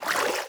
swim2.wav